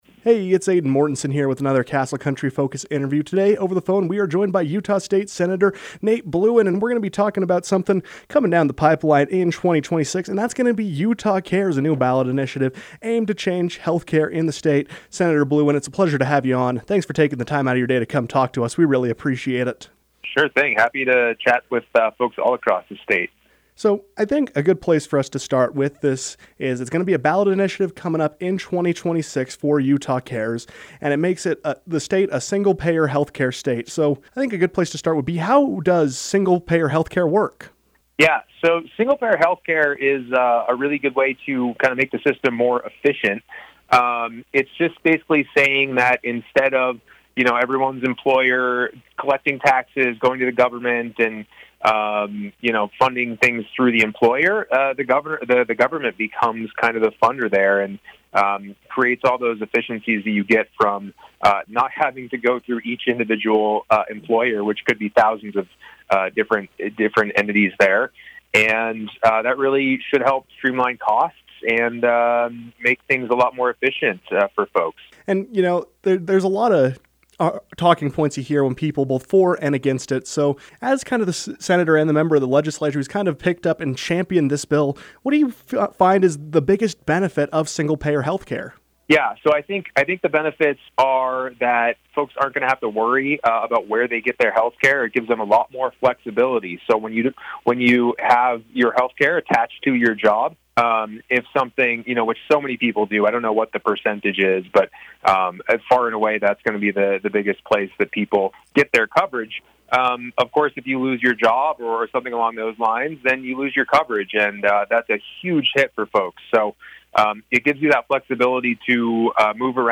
Looking to simplify the health care process, Utah Cares is launching a new ballot initiative to introduce single-payer health care to the state. State Sen. Nate Blouin joined the KOAL newsroom to discuss this movement and how he sees it benefiting the public.